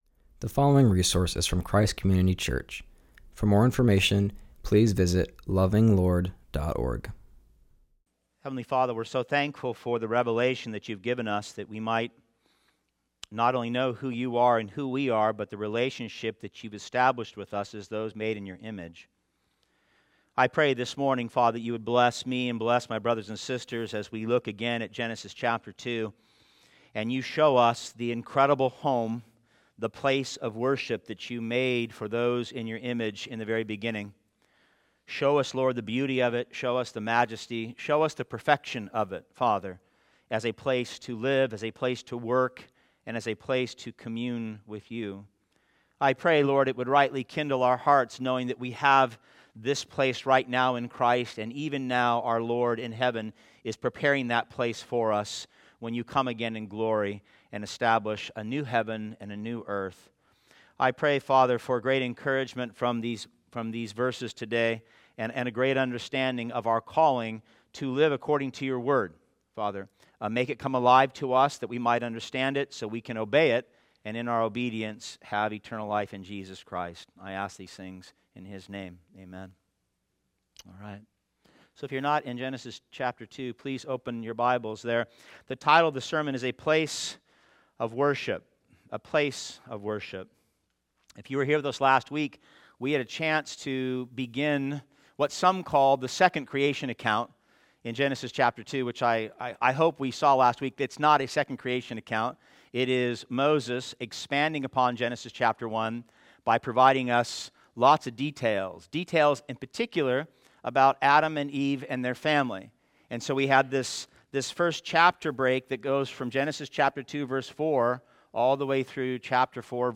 continues our series and preaches from Genesis 2:8-17.